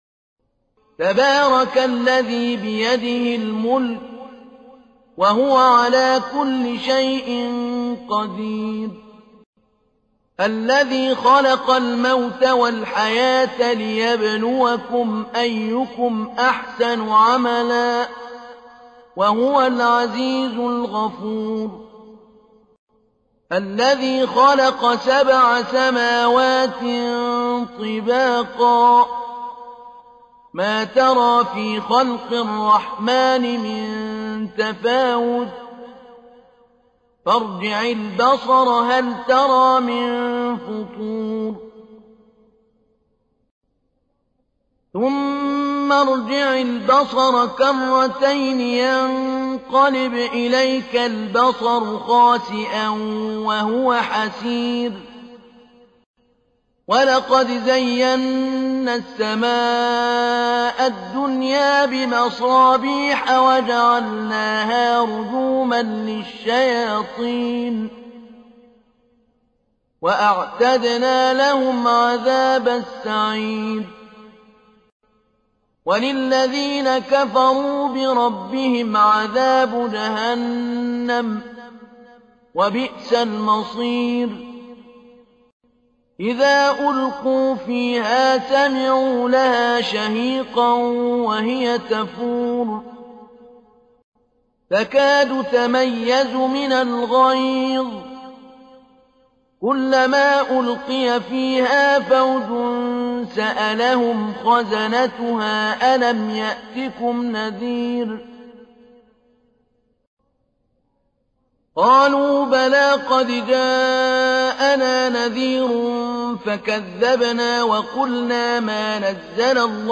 تحميل : 67. سورة الملك / القارئ محمود علي البنا / القرآن الكريم / موقع يا حسين